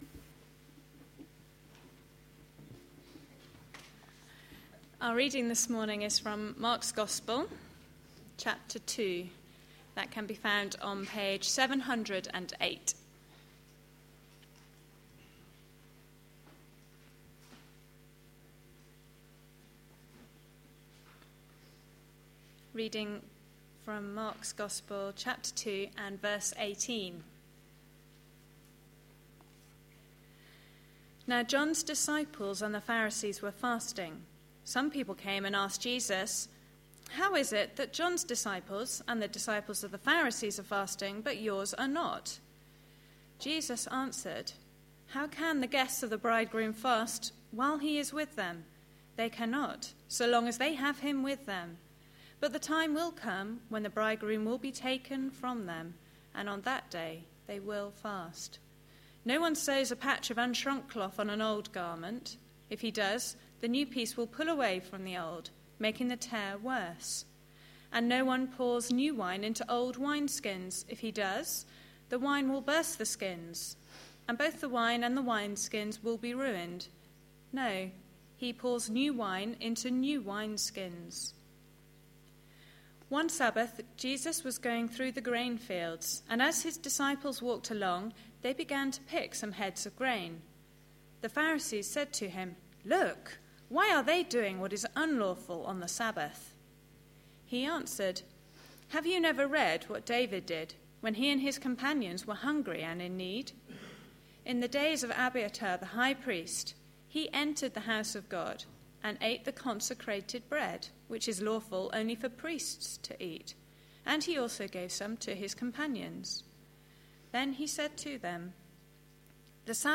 A sermon preached on 23rd October, 2011, as part of our Mark series.